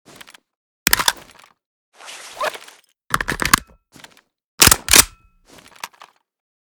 usas12_reload_empty.ogg.bak